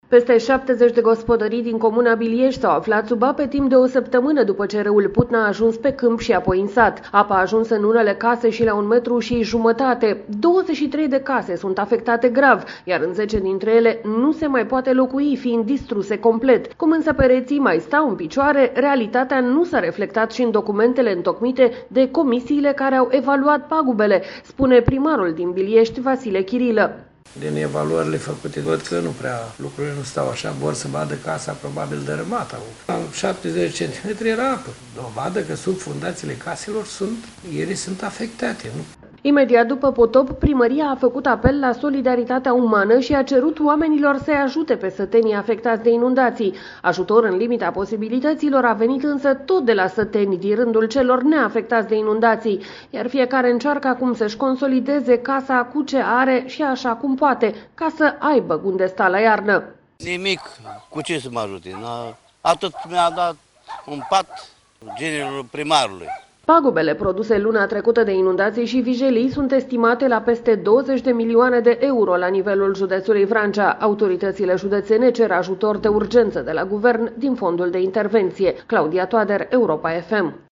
Cum, însă, pereții mai stau în picioare, realitatea nu s-a reflectat și în documentele întocmite de comisiile care au evaluat pagubele, spune primarul din Biliești, Vasile Chirilă.
Se vede cu ochiul liber gradul de afectare a locuinței și se poate auzi și cu urechea cum sună peretele care e ca un burete plin.